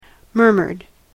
/ˈmɝmɝd(米国英語), ˈmɜ:mɜ:d(英国英語)/